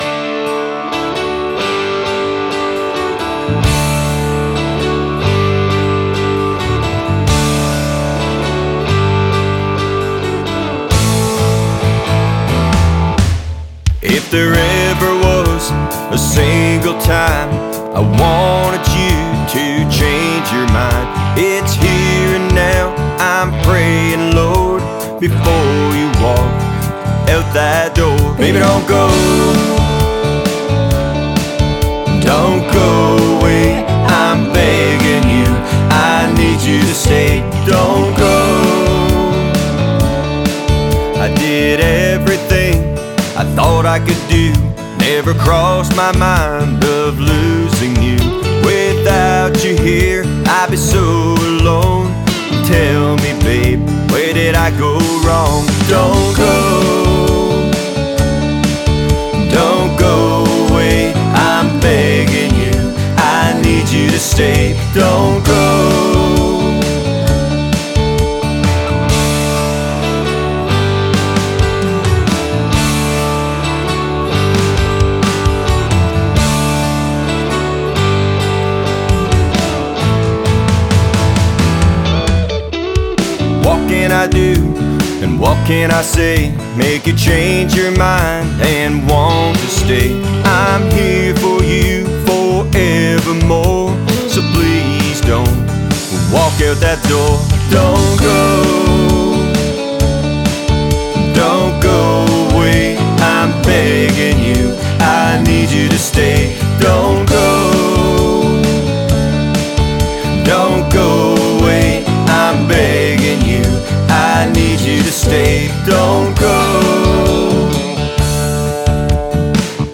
A traditional Country song with a splash of Country rock.
a happy, punchy melody